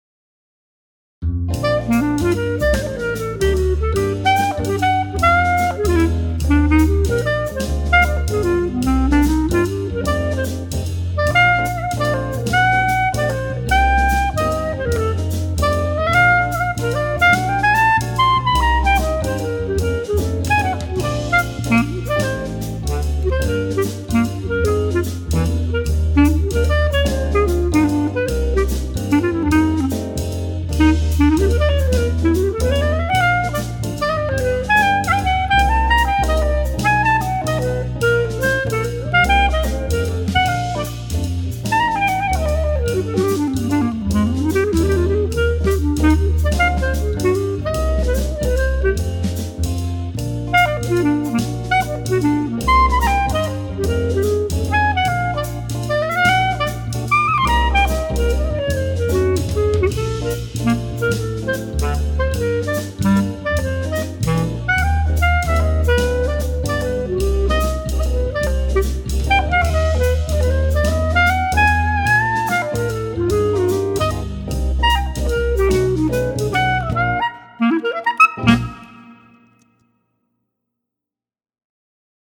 46 Jazz Studies for Clarinet over three graded books